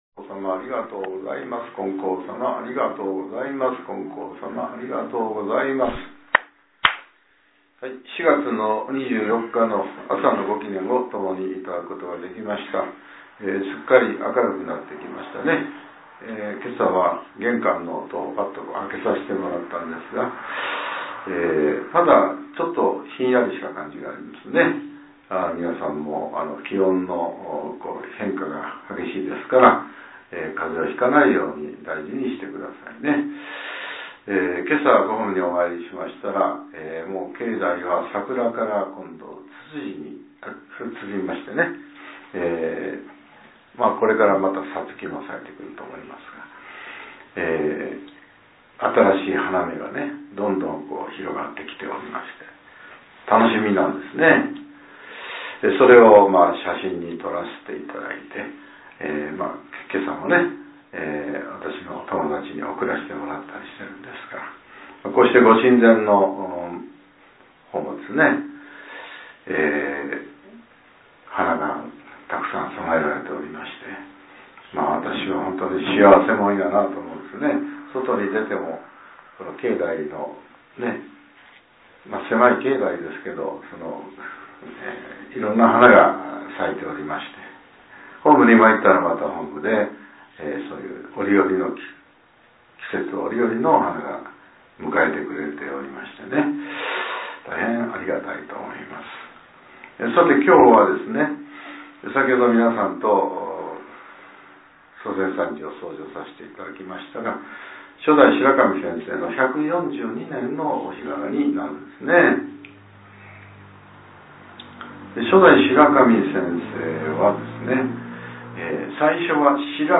令和７年４月２４日（朝）のお話が、音声ブログとして更新されています。